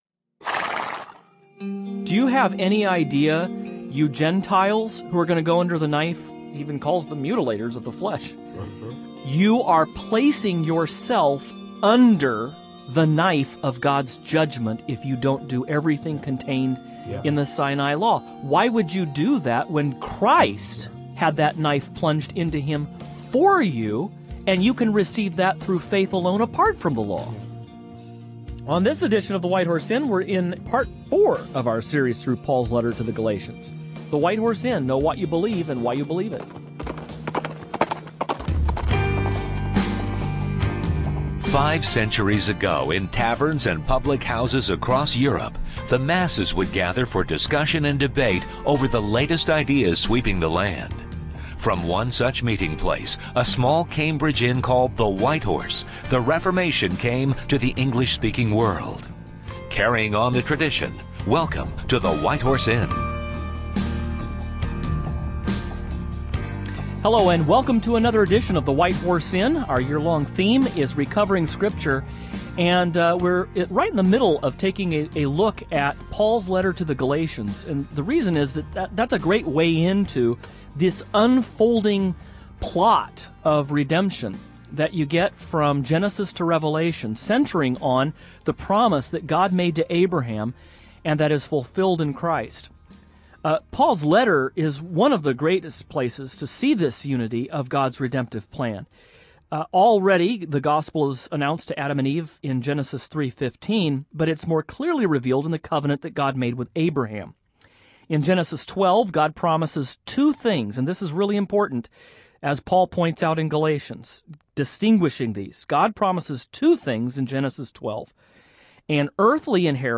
On this edition of the program the hosts discuss Paul's allegory of two mountains, and two mothers in Galatians chapter 4. Why does the apostle argue that the present city of Jerusalem corresponds with Hagar, rather than Sarah?